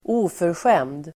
Uttal: [²'o:försjem:d]